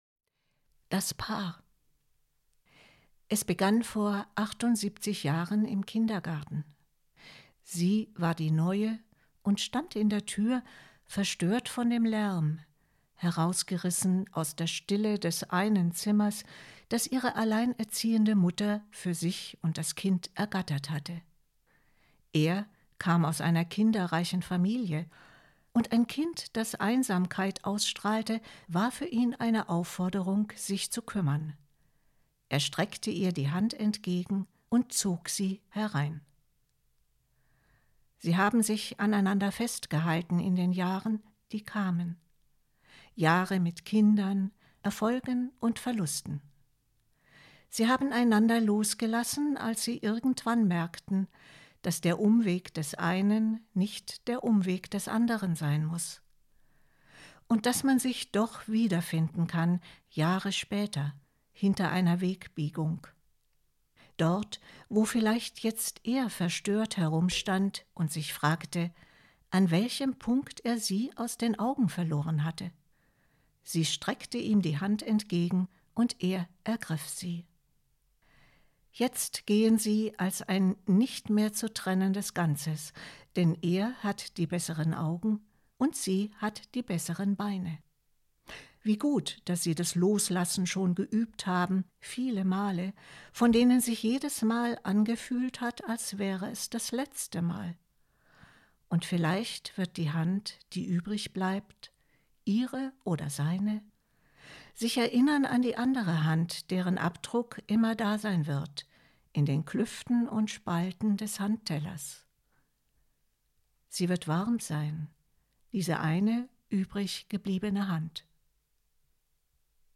Eine Kurzgeschichte